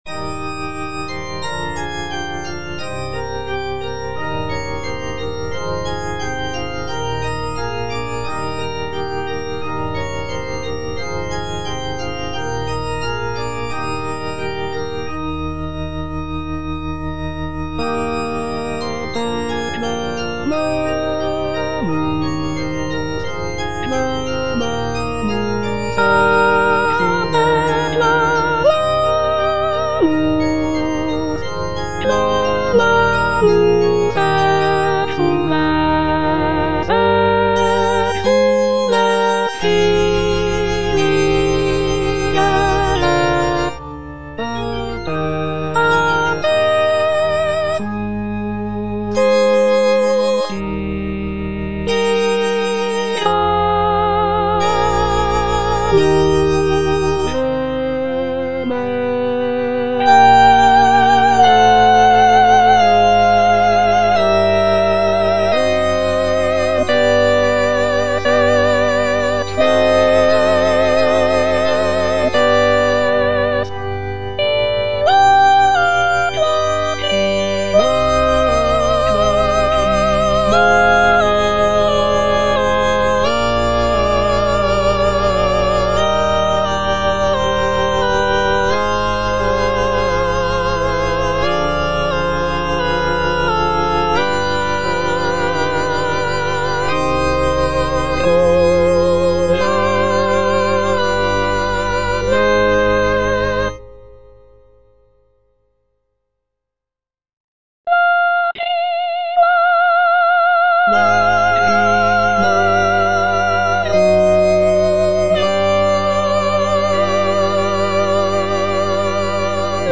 Soprano (Emphasised voice and other voices) Ads stop
sacred choral work